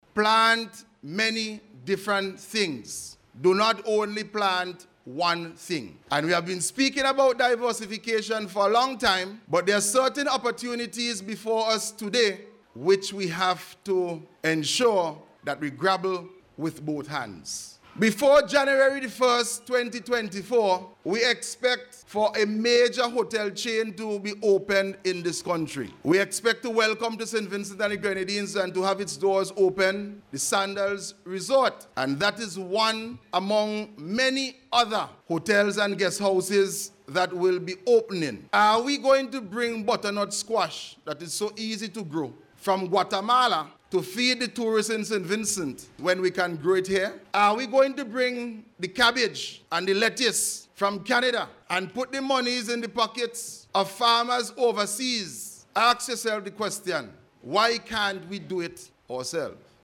Minister Caesar was speaking at an Information and Awareness meeting for farmers at the South Rivers Methodist School.